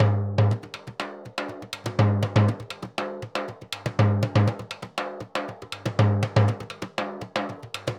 Surdo 2_Salsa 120_1.wav